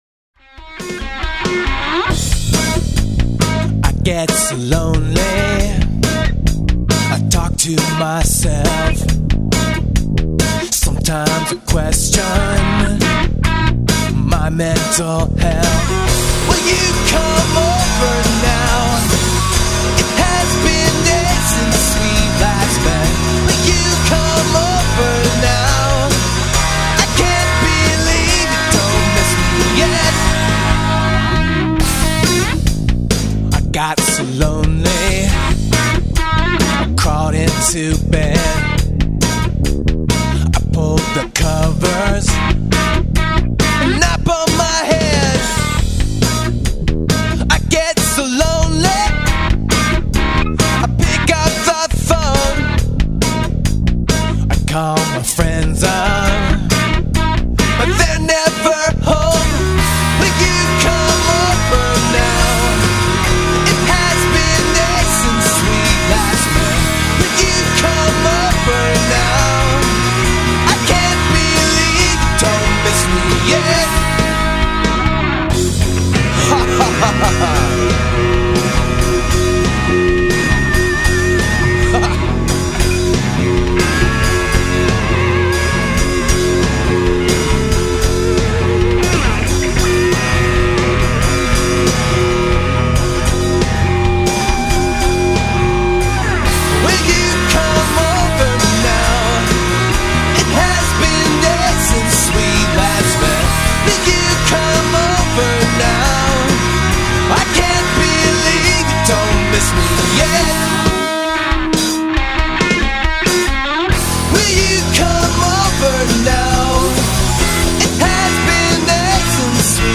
Polyrhythmic, eclectic, very modern 'art funk-rock' music.